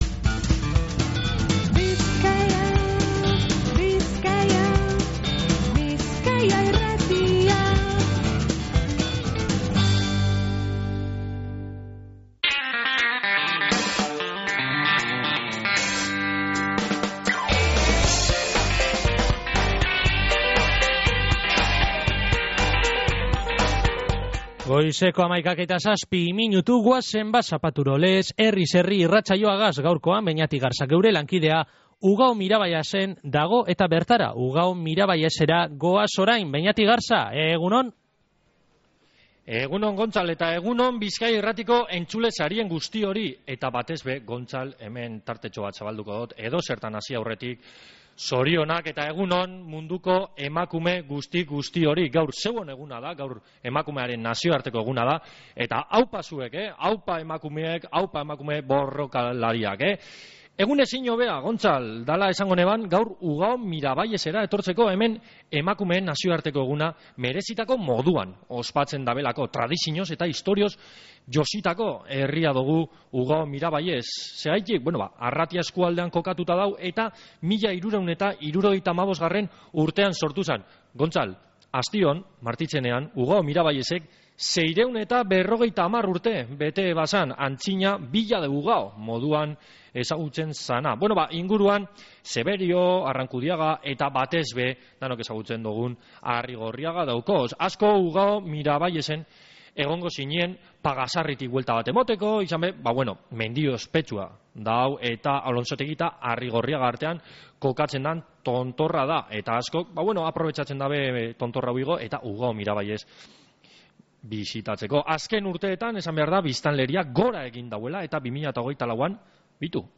Ugao-Miraballesetik ospatu dogu Emakumearen Nazinoarteko Eguna | Bizkaia Irratia